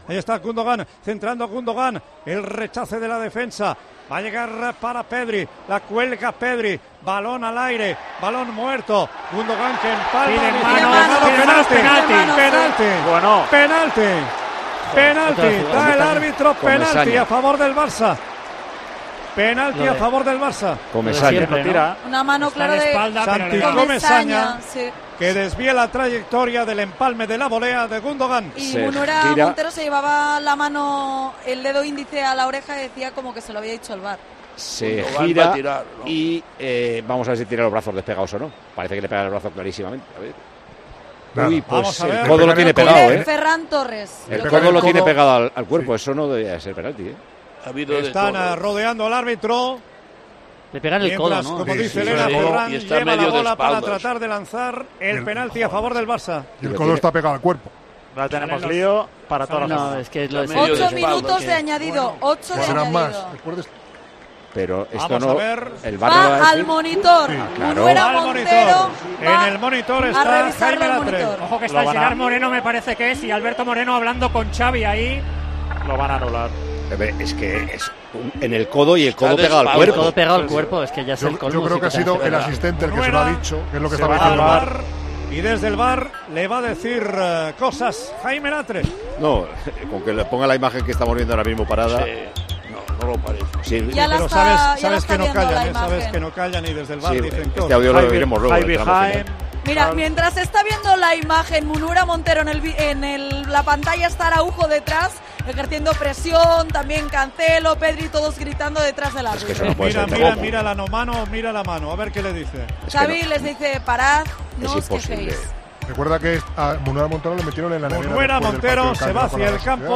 Xavi explota ante las cámaras tras el penalti anulado al Barça: "Es una vergüenza"